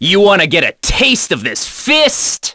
Gladi-vox-get_04.wav